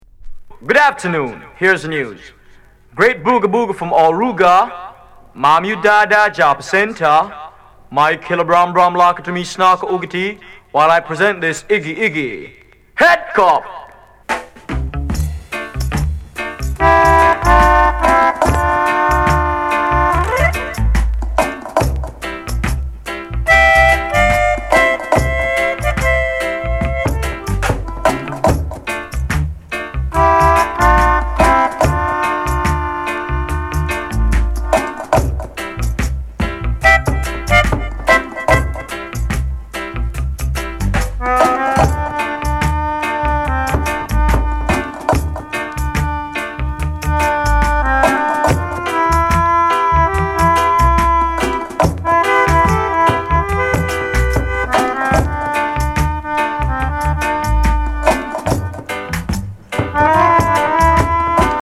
MELODICA INST